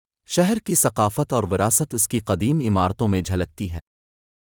70) Scenario: In a travel documentary, the narrator describes,